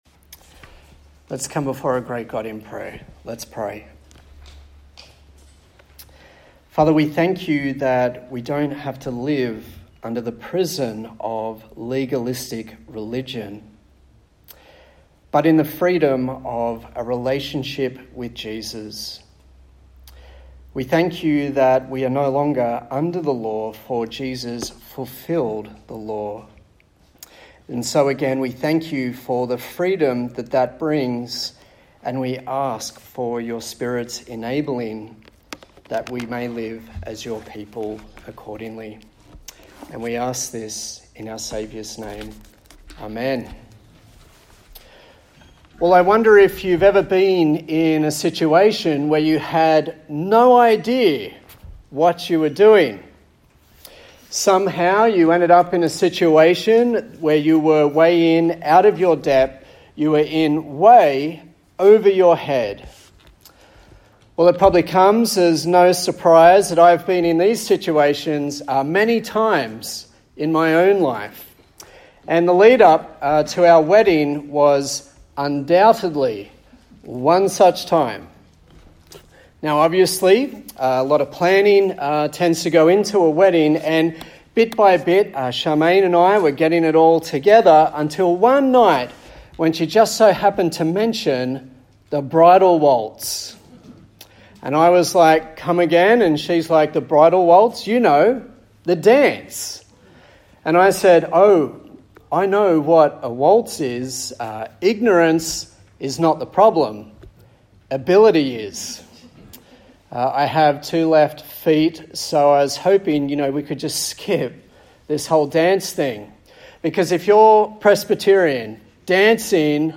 Service Type: TPC@5